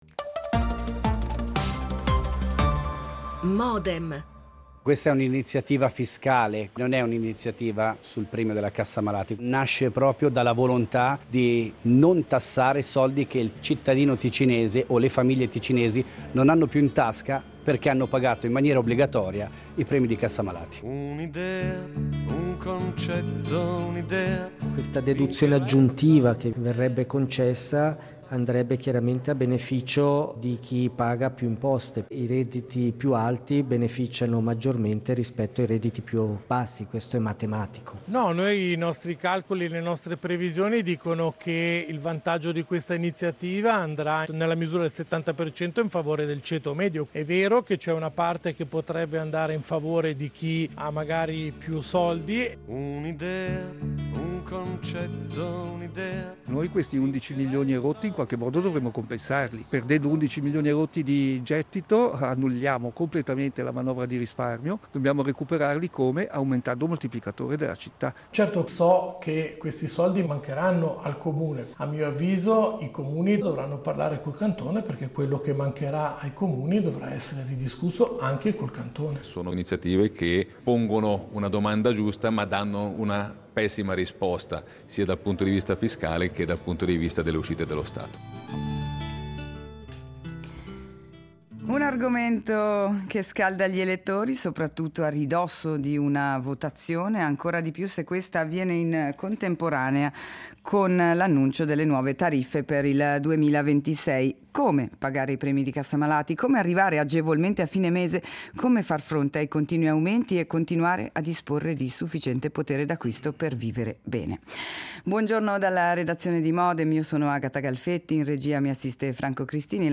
Lo facciamo con due ospiti:
L'attualità approfondita, in diretta, tutte le mattine, da lunedì a venerdì